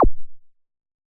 twing.wav